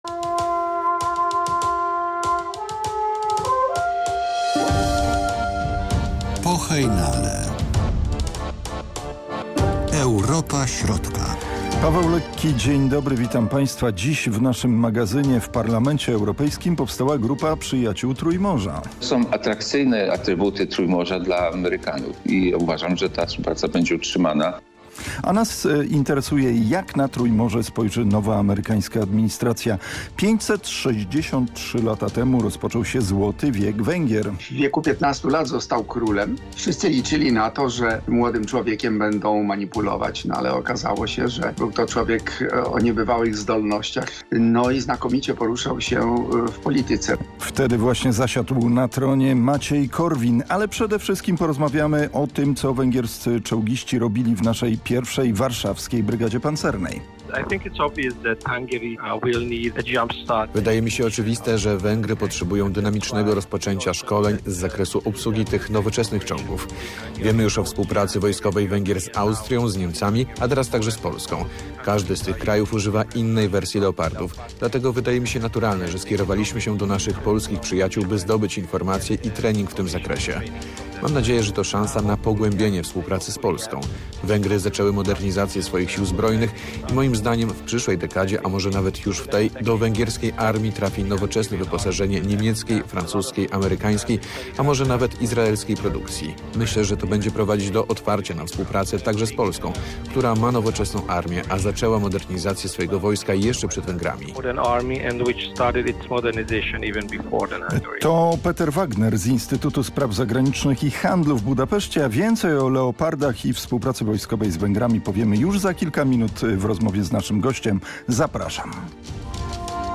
Przygotowywany we współpracy z Instytutem im. W. Felczaka informacyjno-publicystyczny magazyn poświęcony współpracy polsko-węgierskiej, Grupie Wyszehradzkiej i państwom Trójmorza nadawany jest w każdy piątek o godz. 12:42 w radiowej Jedynce.